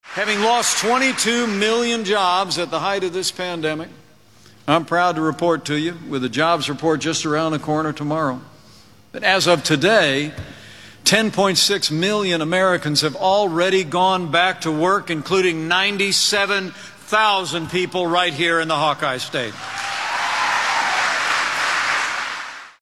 Early this (Thursday) afternoon, Pence spoke to about 275 people in a Carter Lake plant that makes galvanized metal parts for bridges and other structures.
Pence touted President Trump’s appointments to federal courts drawing huge cheers from the crowd. Pence promised a Covid vaccine would be available before the end of this year and the country’s economy would continue its comeback.